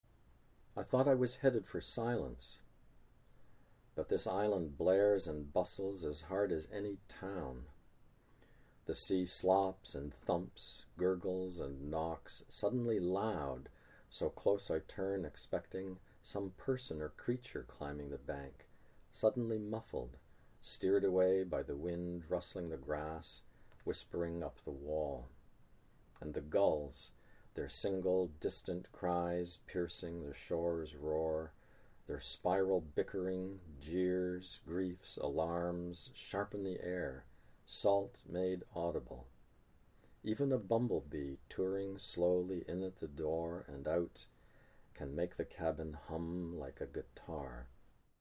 John Steffler reads [I thought I was headed for silence] from The Grey Islands